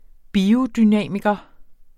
Udtale [ ˈbiːodyˌnæˀmigʌ ]